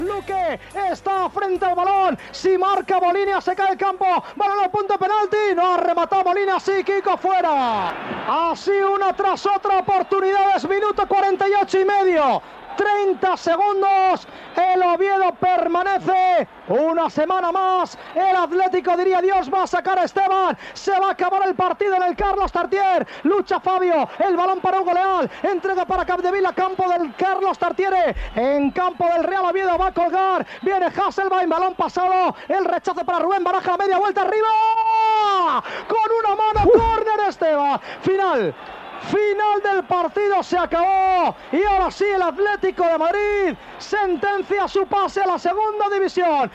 Transmissió del final del partit de la primera divisió de la lliga masculina de futbol entre el Real Oviedo i l'Atlético de Madrid. Amb aquell resultat, l'Atlético de Madrid baixava a segona divisió
Esportiu